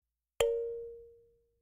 دانلود آهنگ چشمک زدن 2 از افکت صوتی انسان و موجودات زنده
دانلود صدای چشمک زدن 2 از ساعد نیوز با لینک مستقیم و کیفیت بالا
جلوه های صوتی